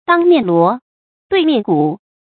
当面锣，对面鼓 dāng miàn luó，duì miàn gǔ
当面锣，对面鼓发音